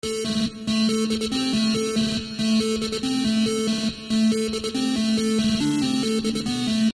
吉他曲
描述：2声音：电吉他+方形合成器
Tag: 140 bpm Trance Loops Guitar Electric Loops 1.16 MB wav Key : Unknown